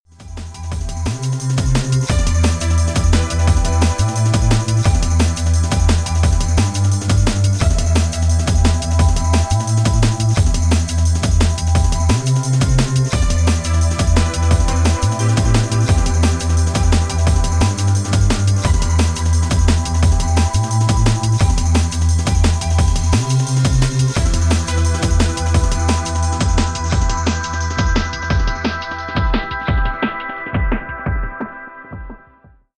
I found an early extract from the last track I’m working on, a liquid dnb try… enjoy & pray for me to finish it someday.